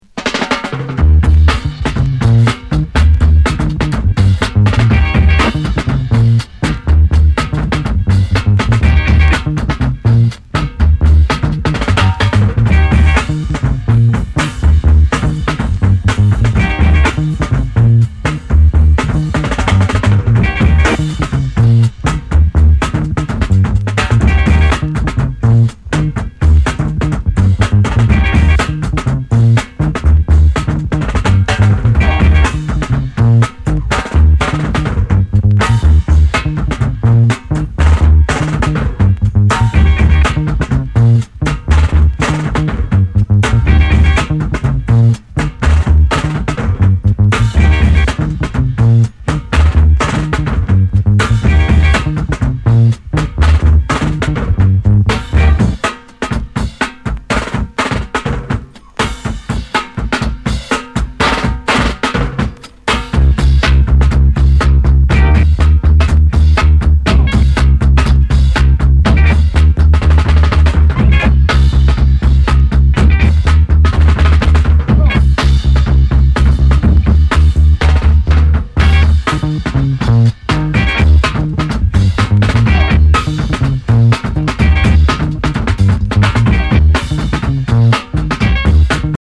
銃声やハモンド効かせたスキンズ・チューンなどルーディー・チューン満載！